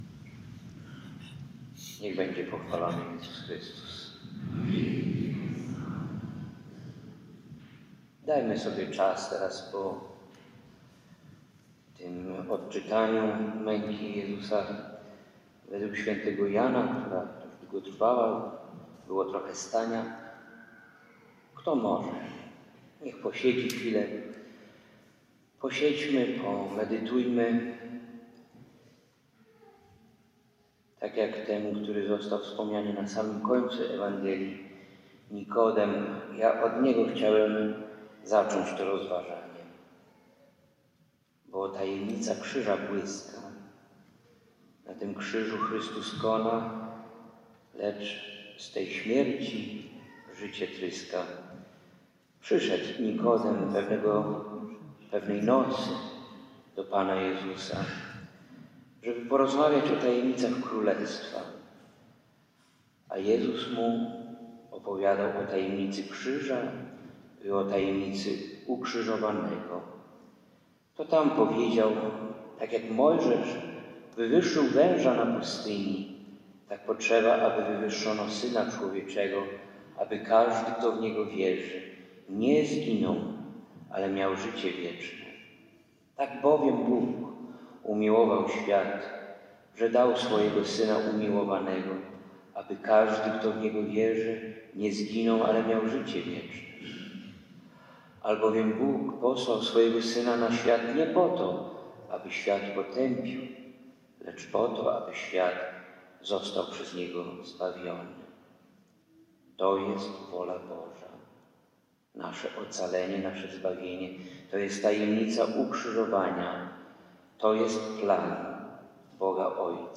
homilia-Wielki-Piatek-2025.mp3